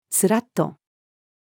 すらっと-female.mp3